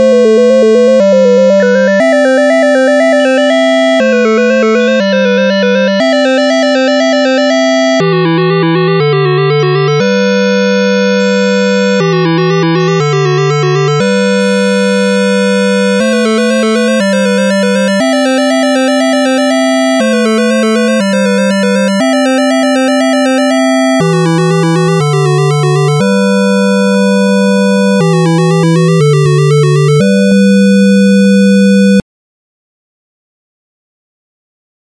These songs are created with AutoCAD.